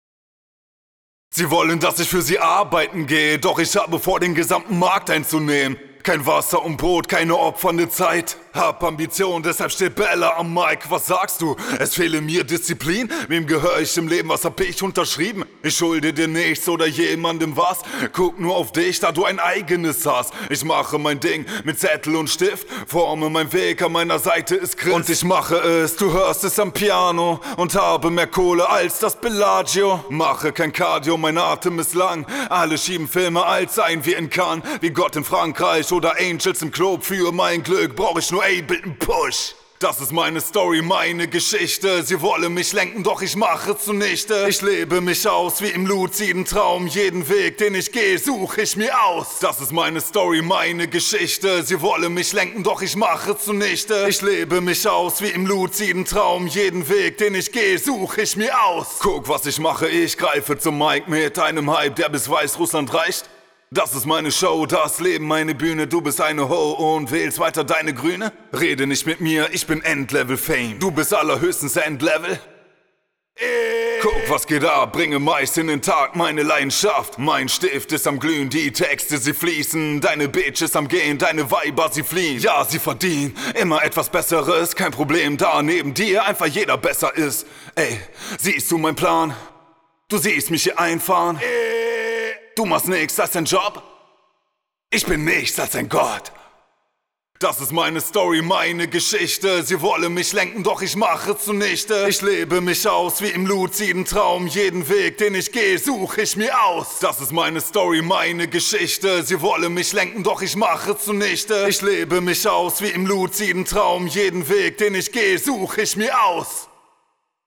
Naja, die doch recht stark unterschiedlichen Pegel kann man eindeutig hören und sehen - finde ich.